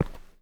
mining sounds
ROCK.5.wav